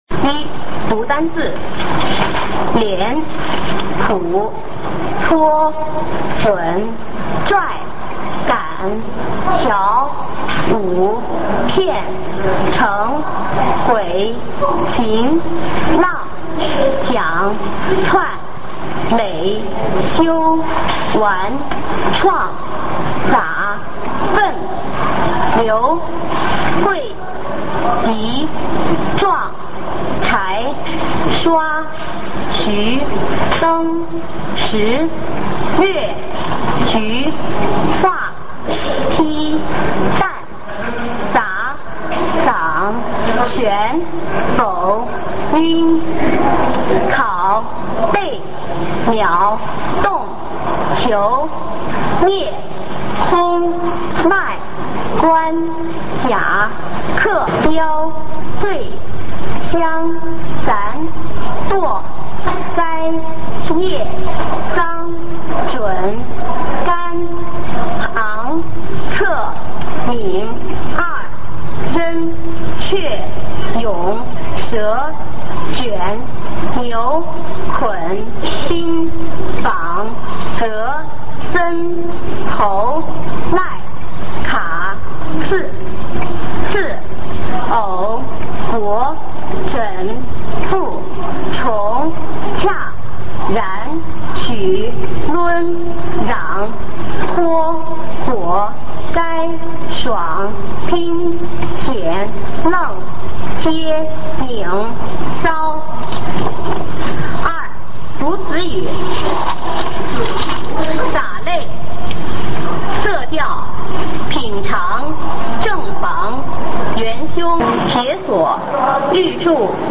首页 视听 学说普通话 等级示范音频
普通话水平测试一级乙等示范读音